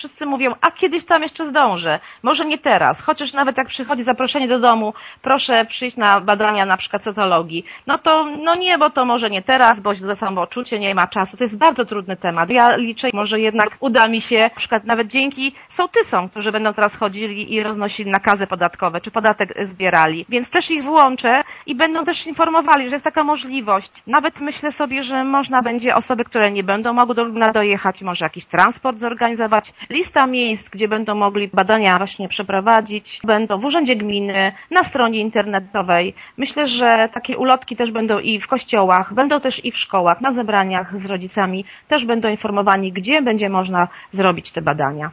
Zastępca wójta Urszula Paździor, która koordynuje akcję w gminie Głusk, przyznaje, że zadanie nie będzie łatwe, ale spróbuje przekonać do skorzystania z badań jak największą liczbę mieszkańców: